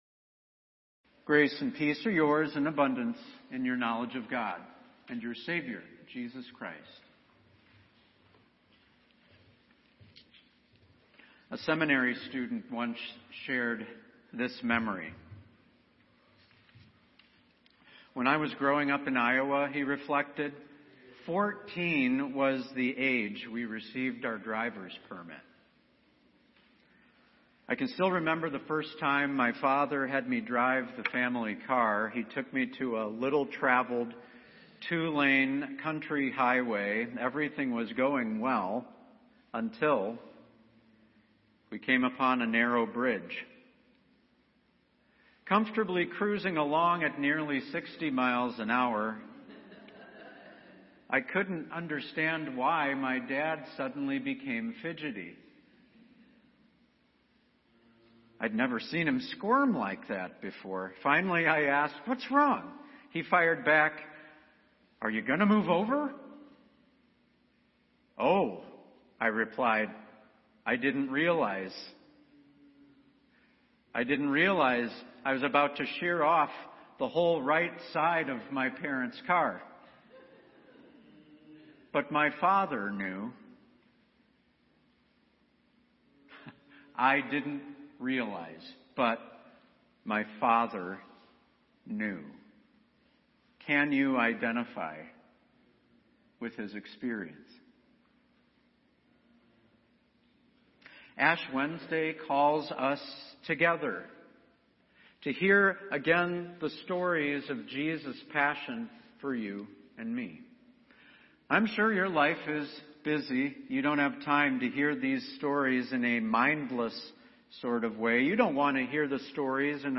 Ash Wednesday